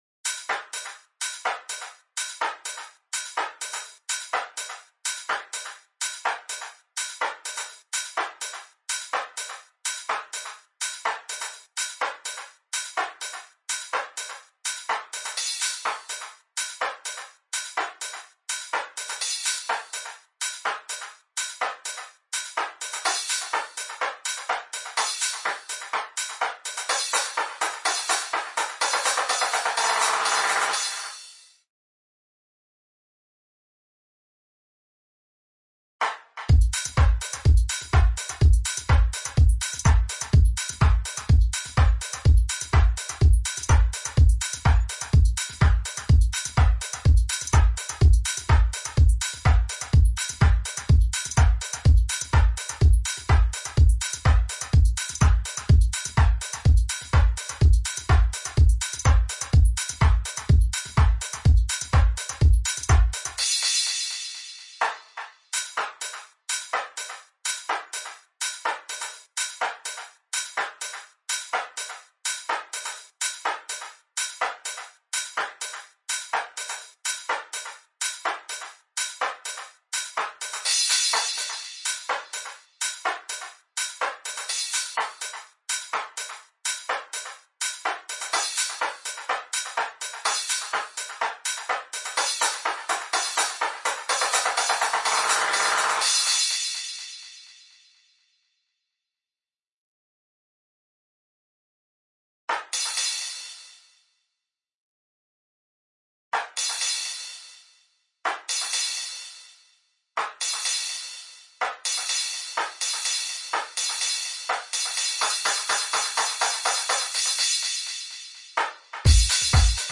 This allowed me to make it longer after each chorus to fill out the sound more whilst everything had dropped out.
Here is a (compressed) bounce of all the drum parts with effects and automation on:
Drums-with-FX-amp-Auto.mp3